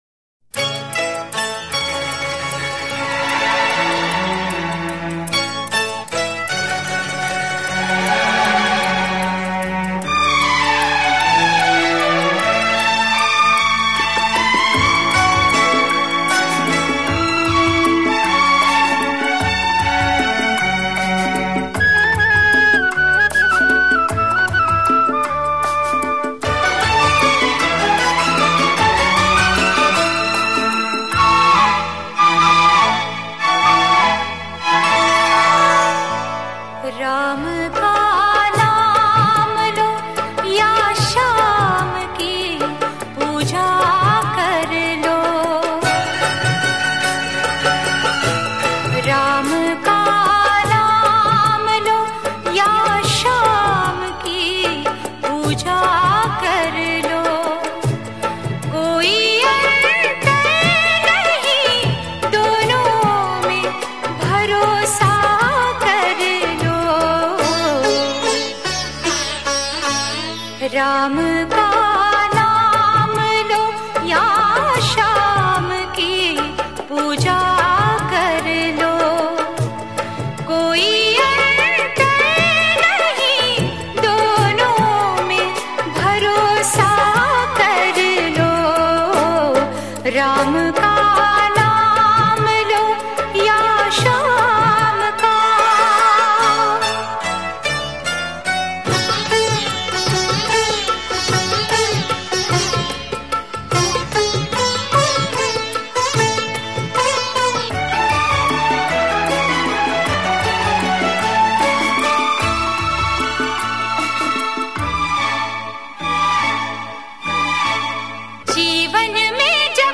Devotional Songs > Shree Ram Bhajans